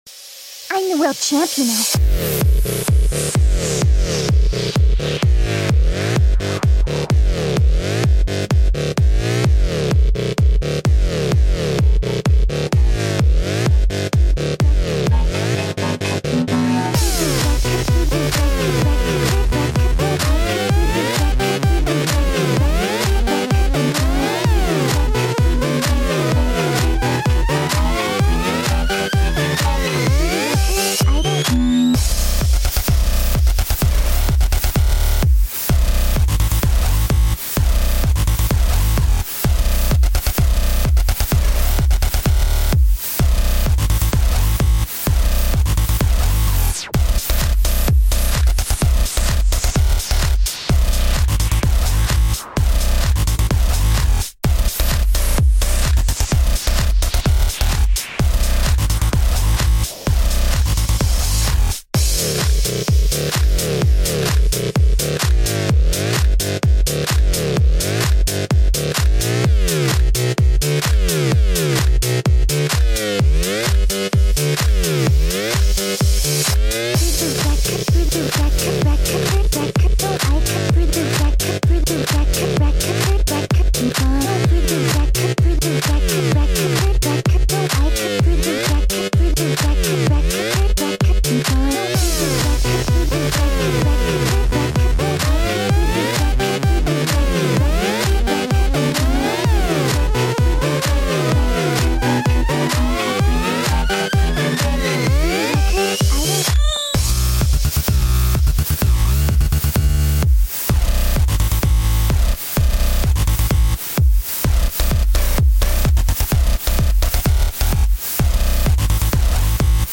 I love making EDM.
BPM: 128